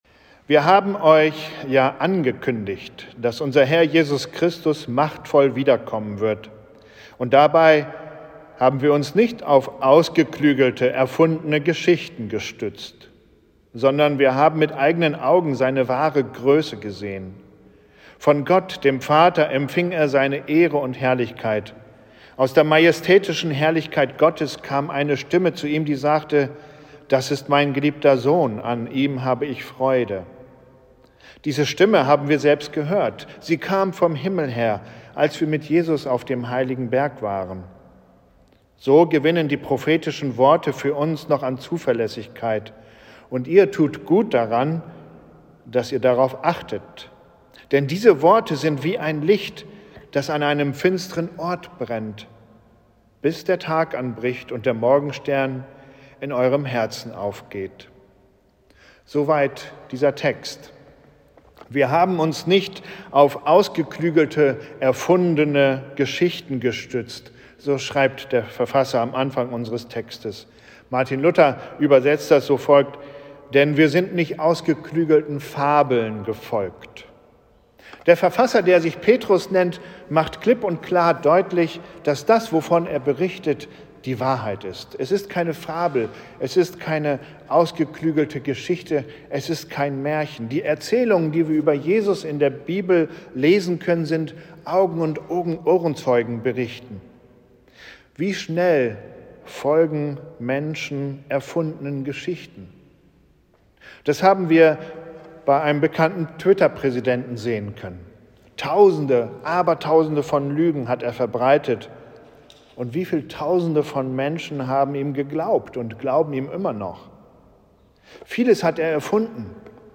Gottesdienst am 31.01.2021